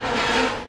jammed.ogg